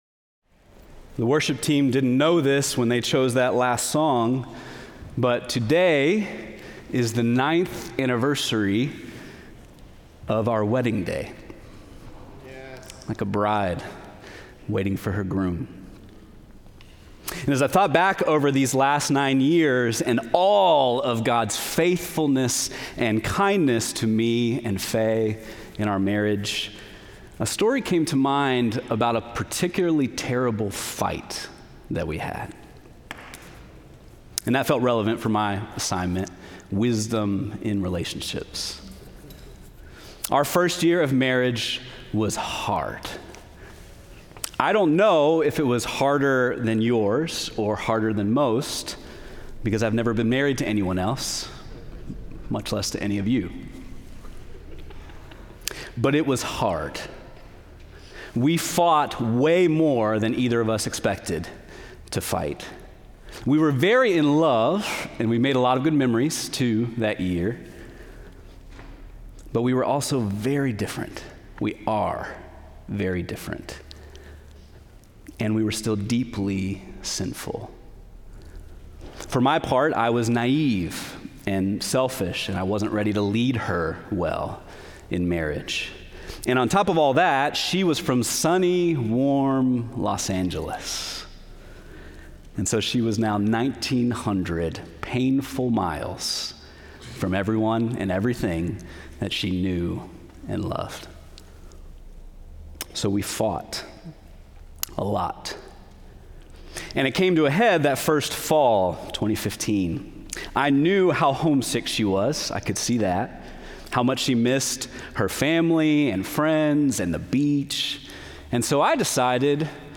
Bethlehem College and Seminary | Minneapolis